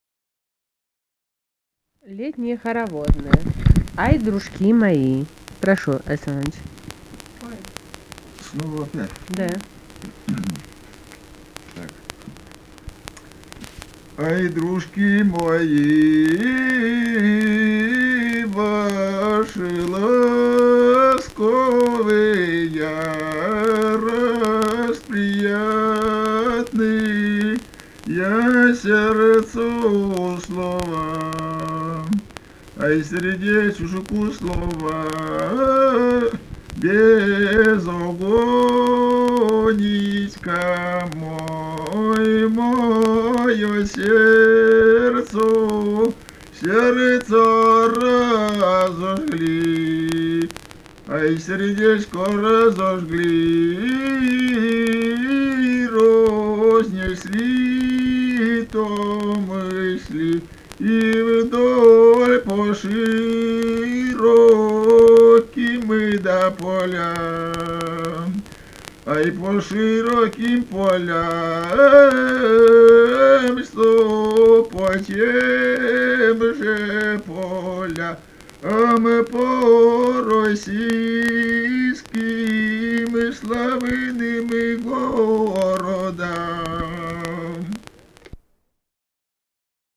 полевые материалы
«Ай, дружки мои» (хороводная «летная»).
Архангельская область, с. Койда Мезенского района, 1965, 1966 гг.